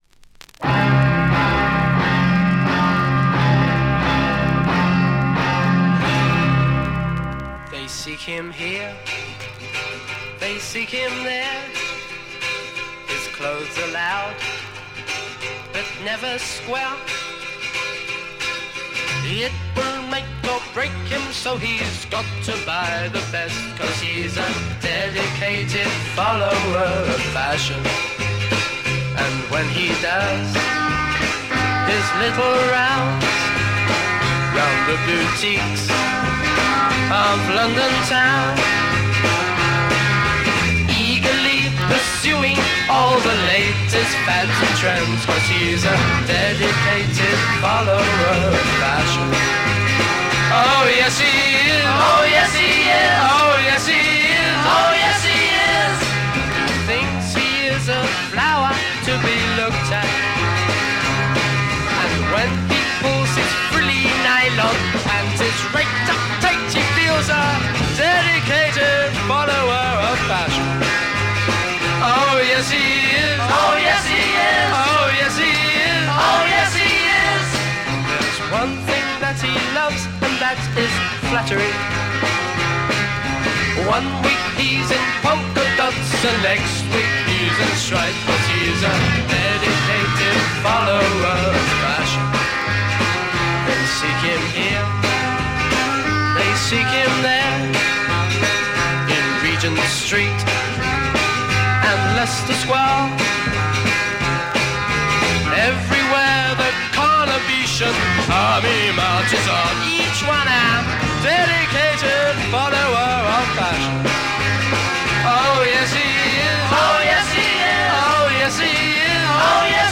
Classic UK Freakbeat garage French EP